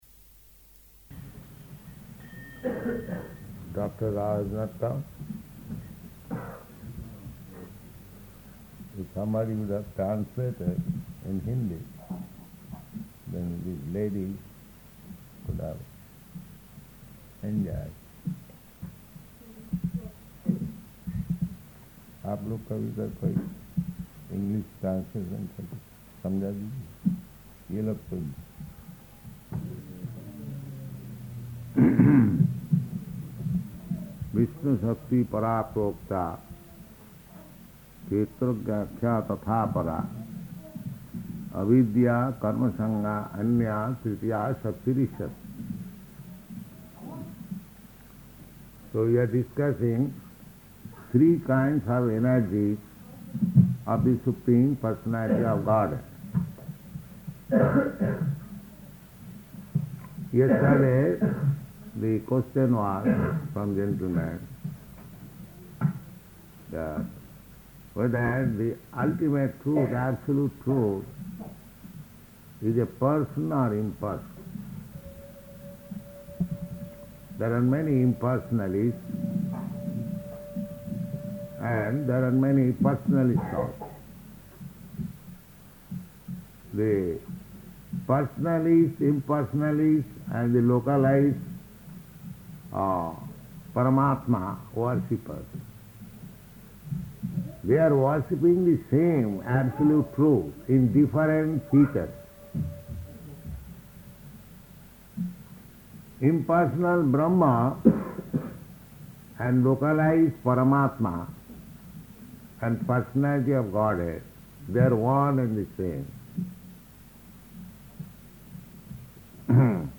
February 17th 1971 Location: Gorakphur Audio file